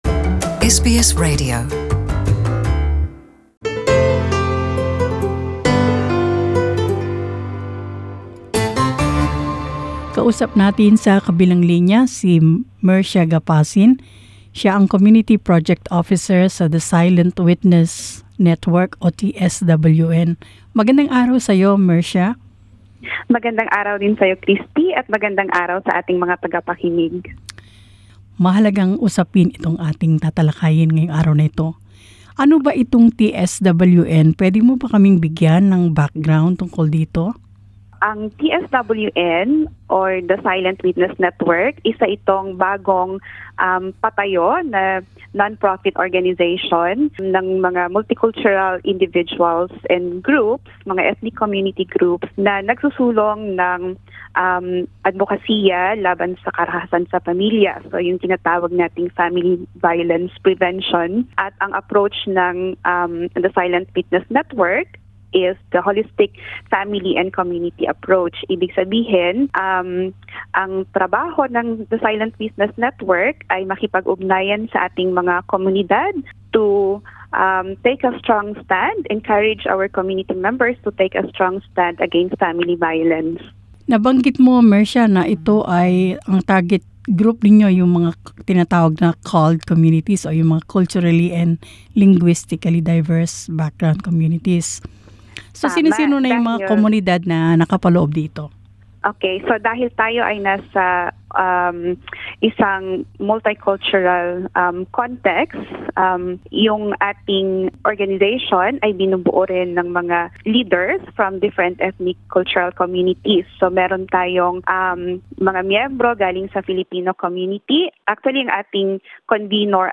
Ang The Silent Witness Network (TSWN) ay isang non-profit organisation na naglalayong magbigay ng pagtataguyod, pagsasanay at edukasyong pangkomunidad upang mahadlangan ang karahasan sa pamilya. Narito ang panayam